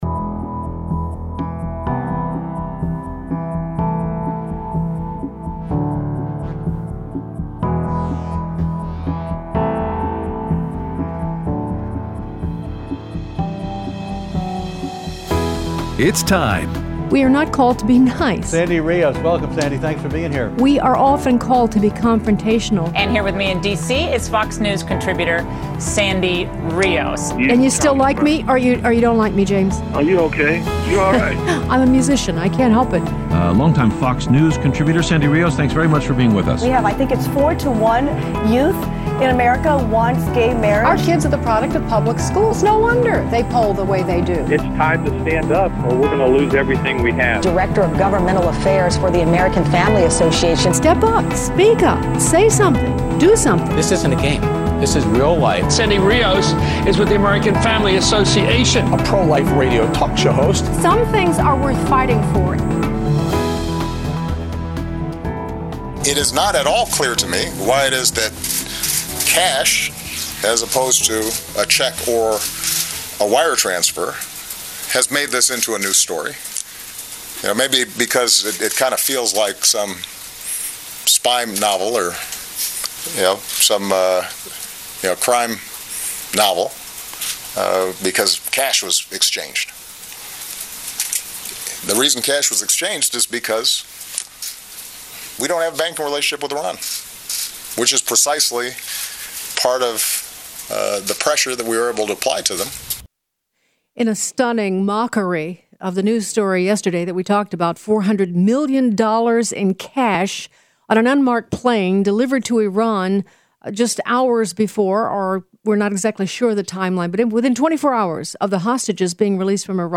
The War on Guns with John Lott, Your Facebook and Emails, and your phone calls